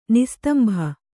♪ nistambha